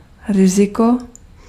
Ääntäminen
US : IPA : [ˈhæ.zəd] UK : IPA : /ˈhazəd/